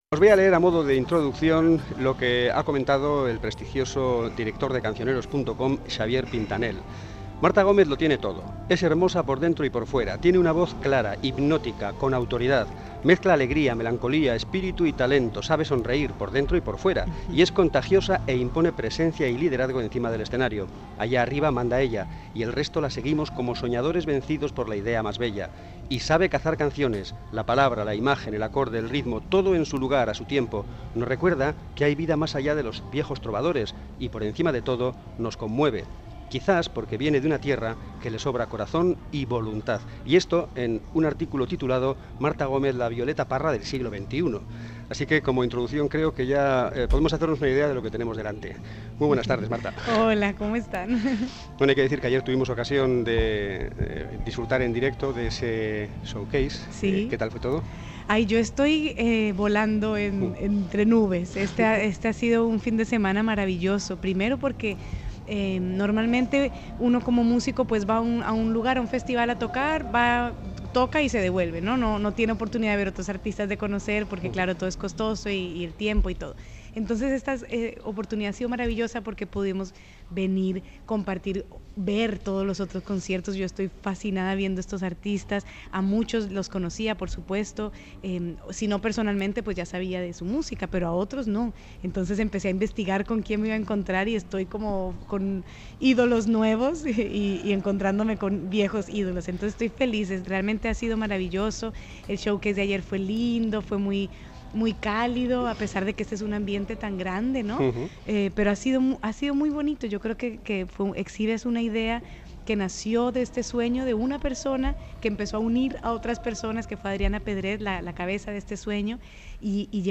Márta Gómez, entrevista para "Aqui Macondo"
Audio: Marta Gómez, Colombia, Aqui Macondo, Entrevista